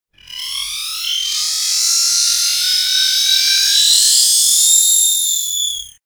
Shriek.ogg